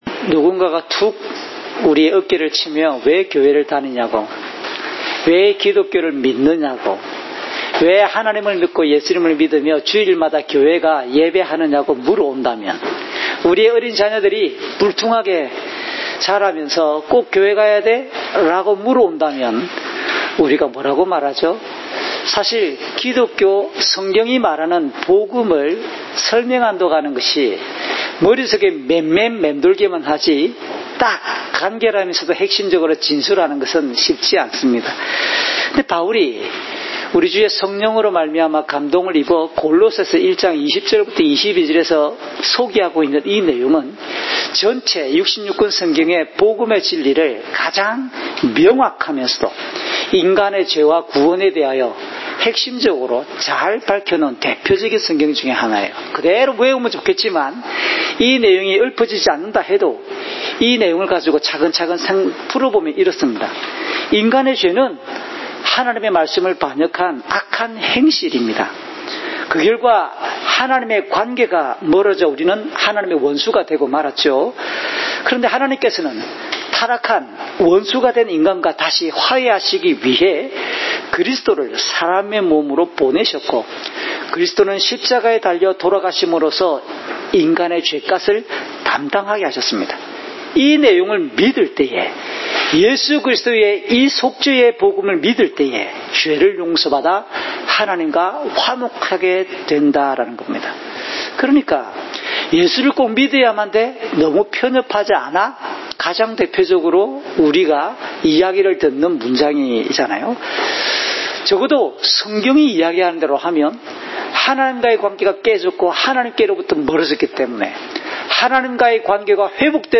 주일설교 - 2020년 7월 12일 "그리스도는 유일한 구원의 길입니다!"(요12:37~50)